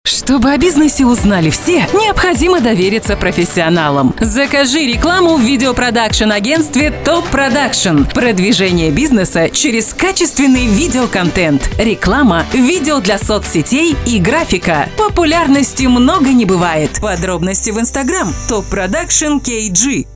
Жен, Рекламный ролик/Зрелый
Микрофон: RODE NT2-A, звуковая карта: Focusrite Scarlett 2Pre USB, профессиональная студия.